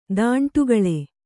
♪ dāṇṭugaḷe